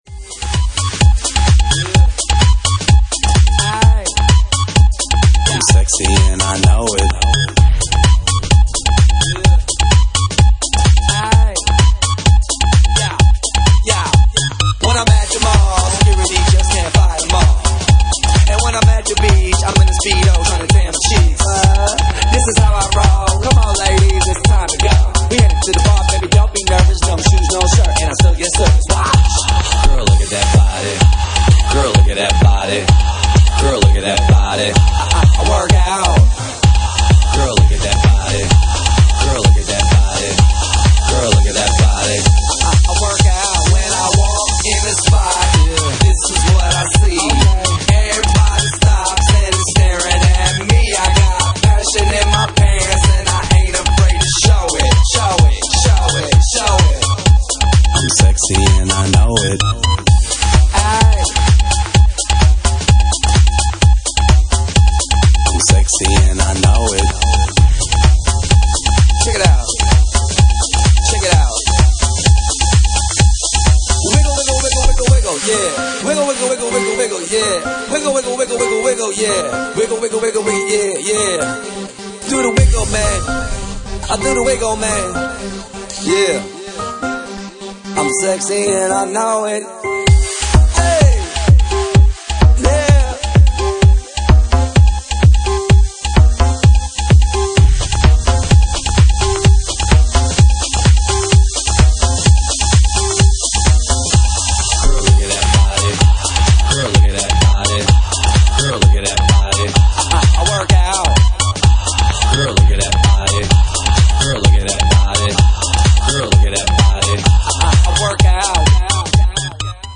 Genre:Jacking House
Jacking House at 129 bpm